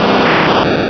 Cri d'Arbok dans Pokémon Rubis et Saphir.